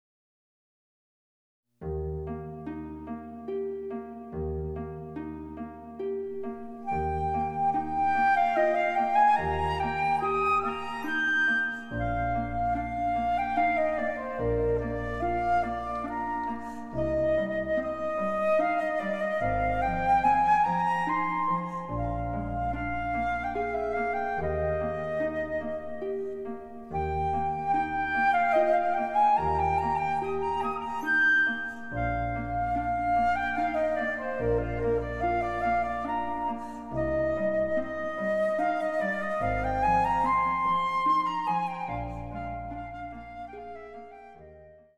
伴奏つきでフルートが演奏できる！　RJPフルート音楽叢書
★フルートの名曲をピアノ（ハープ）伴奏つきで演奏できる、「ピアノ伴奏ＣＤつき楽譜」です。